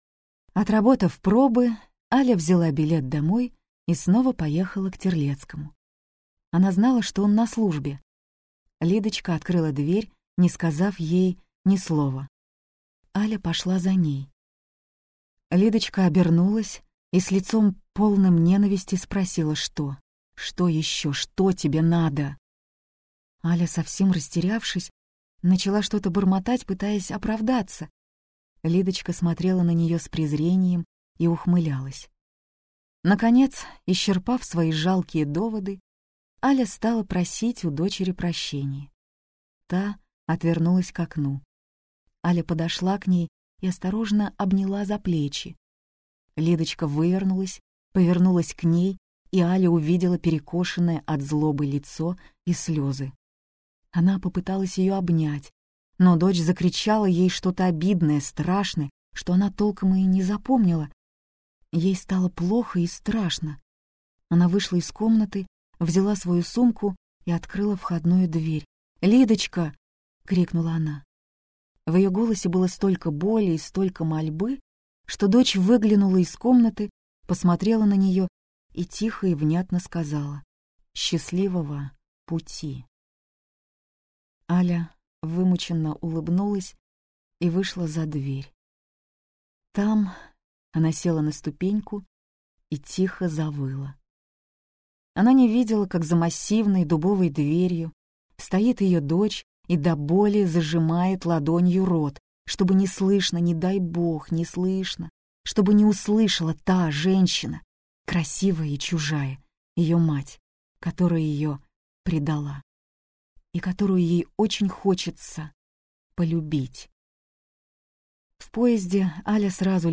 Аудиокнига Женский день - купить, скачать и слушать онлайн | КнигоПоиск